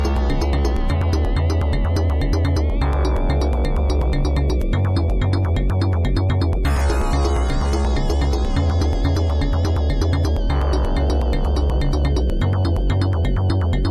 The Genesis Ting